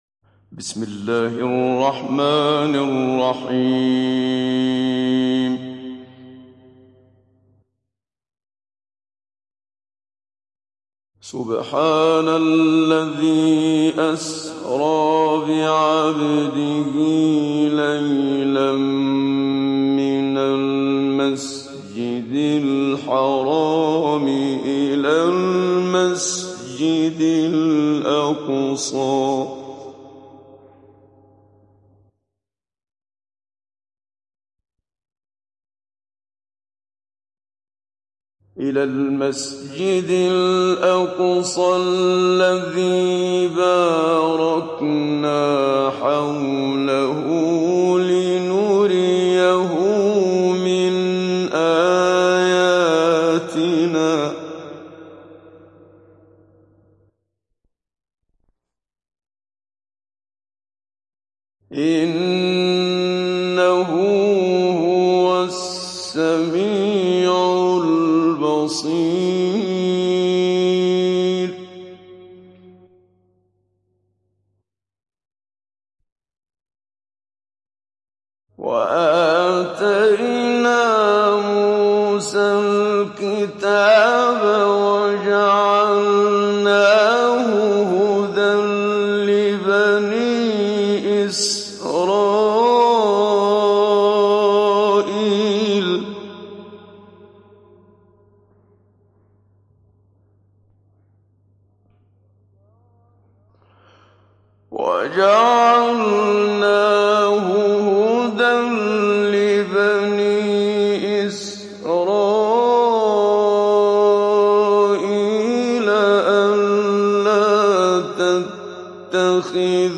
ডাউনলোড সূরা আল-ইসরা Muhammad Siddiq Minshawi Mujawwad